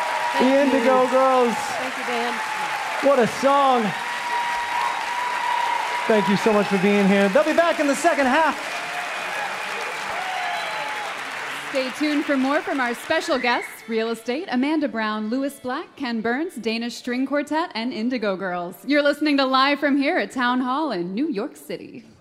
lifeblood: bootlegs: 2020-02-15: the town hall - new york, new york (live from here with chris thile)
(captured from a youtube live stream)